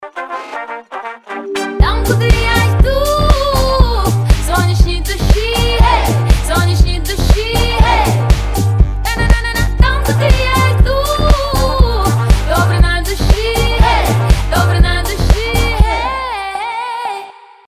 • Качество: 320, Stereo
поп
женский вокал
легкие